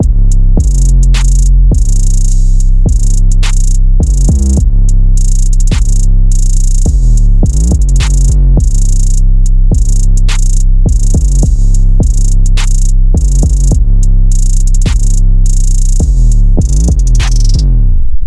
基本的硬式陷阱鼓
描述：这是个硬陷阱循环，有小鼓、hihat、openhat、kick和808，最后有一个效果。
Tag: 105 bpm Trap Loops Drum Loops 3.08 MB wav Key : Unknown